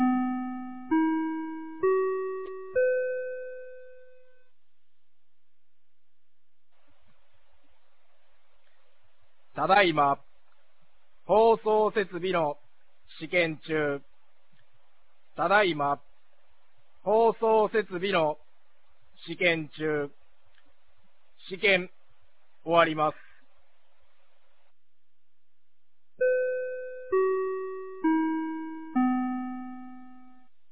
2025年11月25日 18時48分に、美浜町より全地区へ放送がありました。